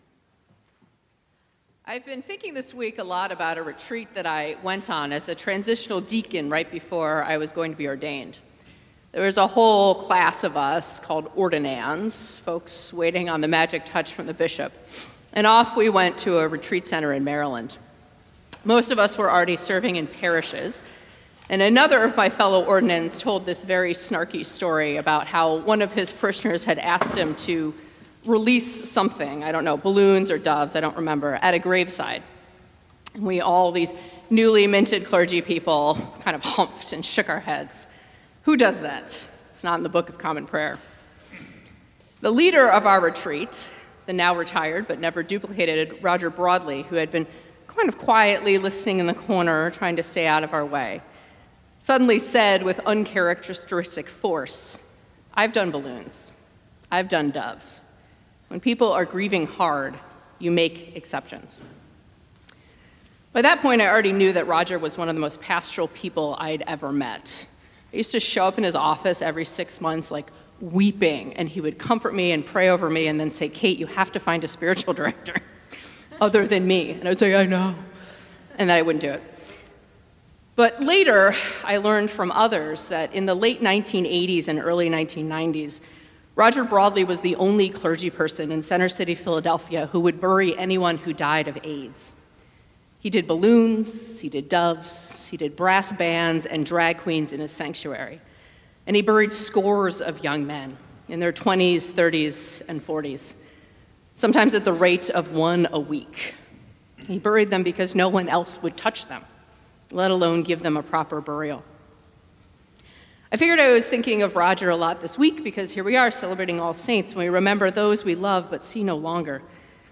Sermons | Emmanuel Episcopal Church